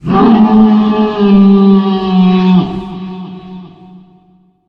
howl_0.ogg